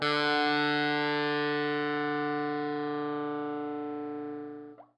SPOOKY    AG.wav